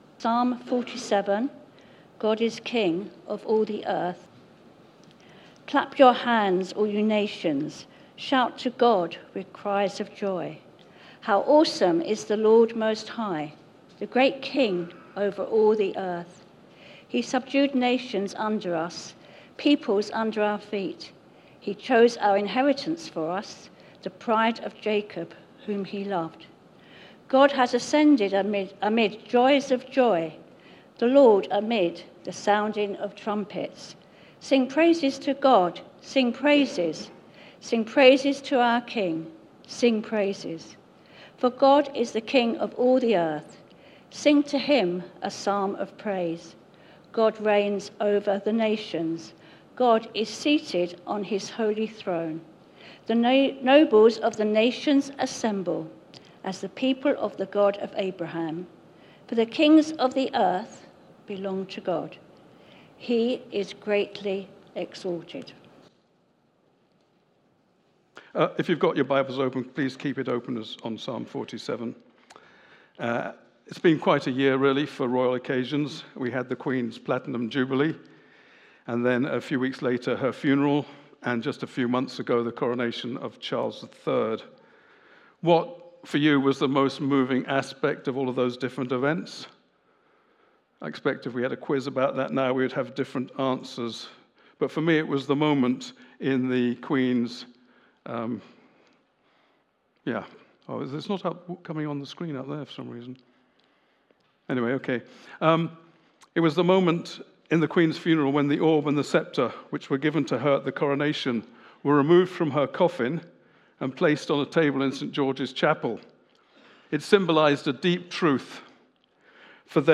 Media for Sunday Service
Theme: Sermon